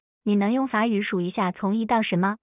single_utterance_16k.wav